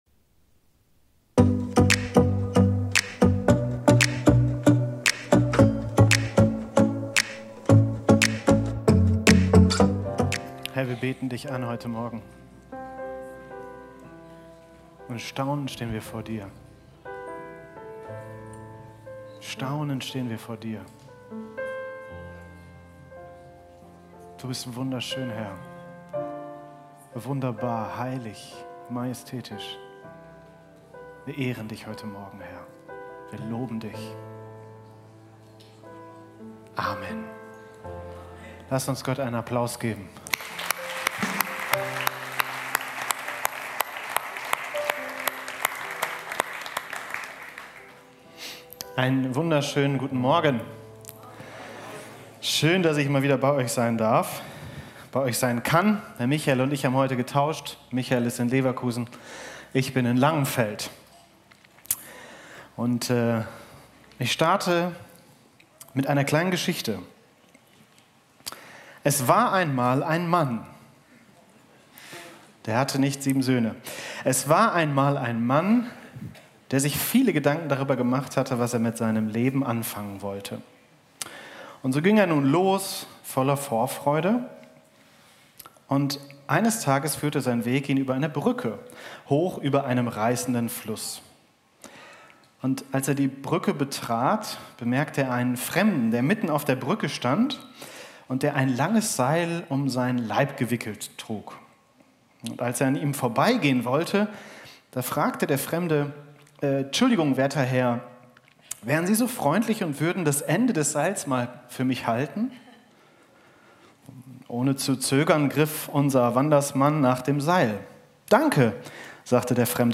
Video und MP3 Predigten
Kategorie: Sonntaggottesdienst Predigtserie: Im Rhythmus seiner Gnade leben